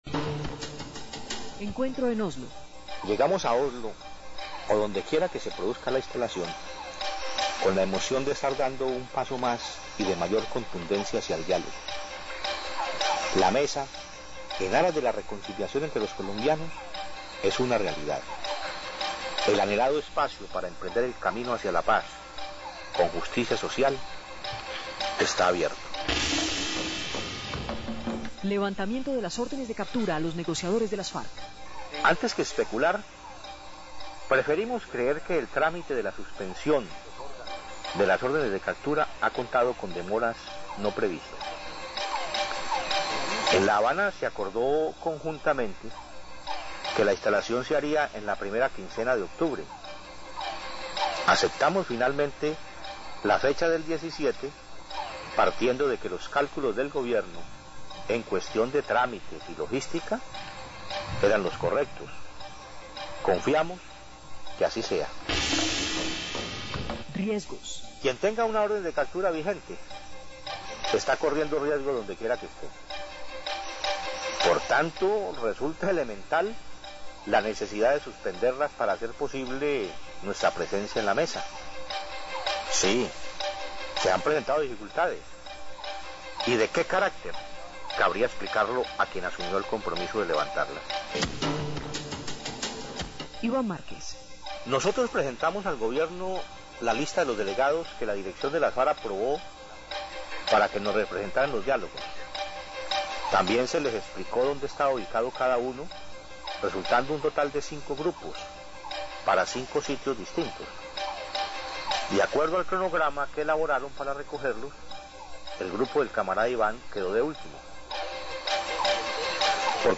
Audio completo de la entrevista:
Entrevista TIMOCHENKO Octubre 15.mp3